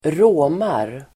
Ladda ner uttalet
råma verb, low, mooGrammatikkommentar: x &Uttal: [²r'å:mar] Böjningar: råmade, råmat, råma, råmarSynonymer: bröla, bölaDefinition: låta som en ko (sound like a cow)